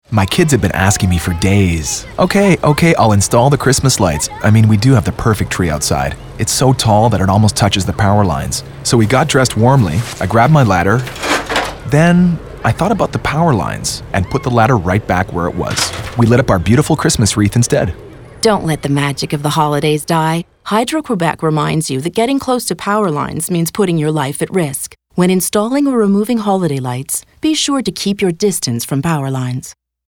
Radio Campaign: safety and holiday lights (1.1 MB) Printed campaign: Don't let the holiday magic die (382.7 KB)
campagne_radio_sapin_noel_securite_en.mp3